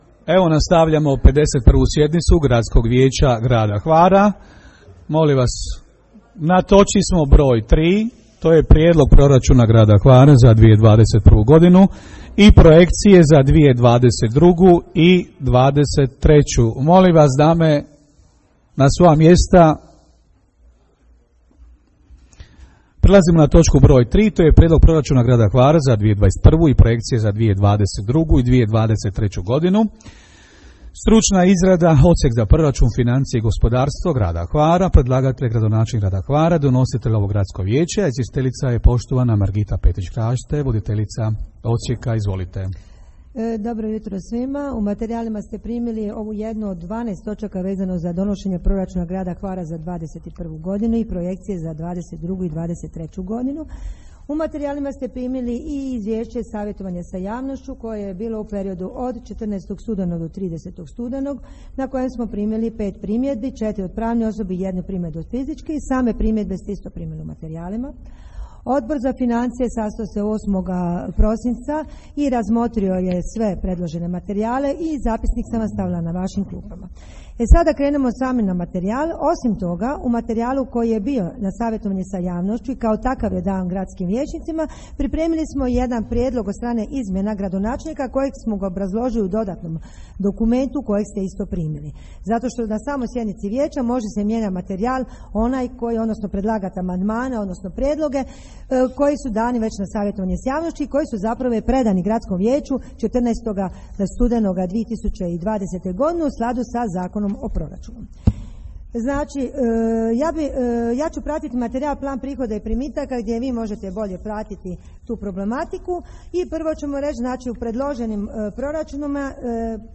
Snimka 3. sjednice Gradskog vijeća Grada Hvara
Audiozapise 3. sjednice Gradskog vijeća Grada Hvara održane 27. srpnja 2021. možete poslušati na poveznicama.